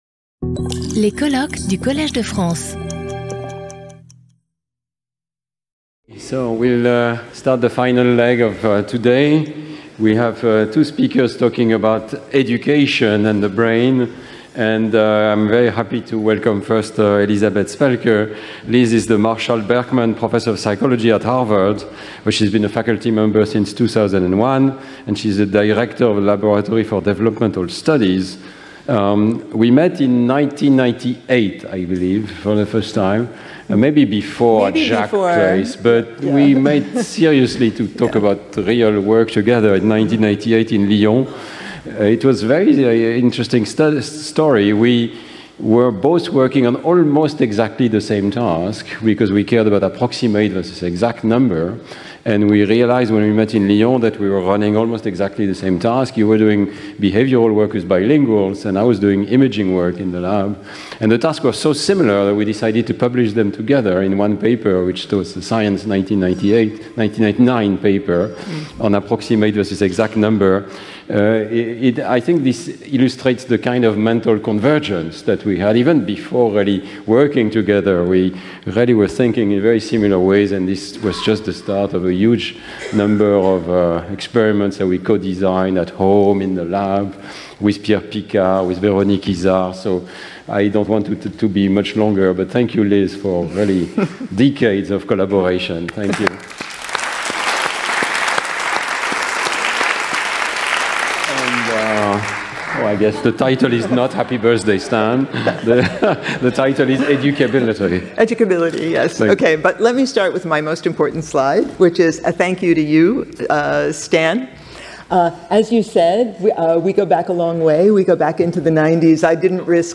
Intervenant(s) Elizabeth Spelke
Colloque